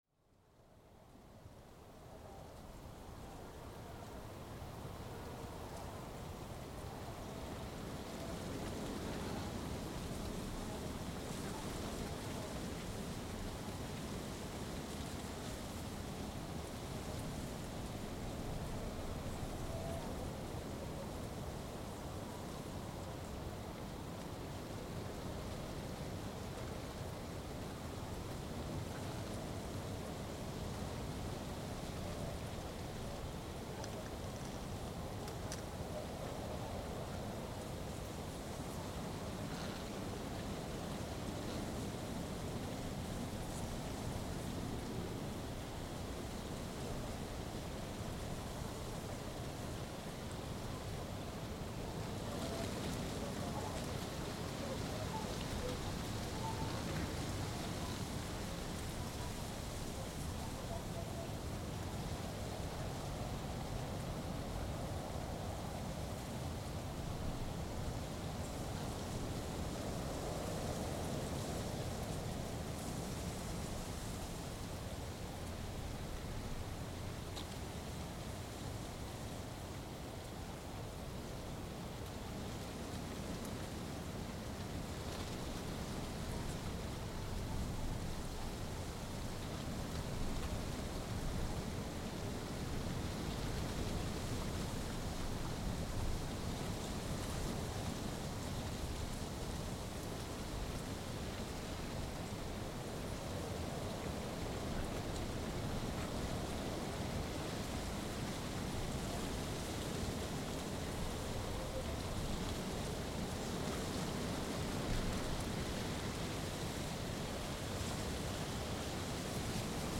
I decided to sleep in the car near the high voltage power line (Byggðalína) on Breiðamerkursandi, south of highway no.1. The next day it was dry, but still very windy.
With Omni & Cardioid microphones as well as Geophone and hydrophone which I use as a contact mic.
But that moment a moisture was in the air, which caused a sizzle noise from the power line, which added a different sound and gave the recording a clearer picture of the recording location. The recording below starts with the audible sound (microphone). Then slowly the contact mics are added . In the end and microphones faded out and you will only hear the sound from the contact mics (geophone and the hydrophone) Because the microphone are located close to the ground in grass under the electricity pylons you will hear lot of „gray noise“ when the wind wipe the grass.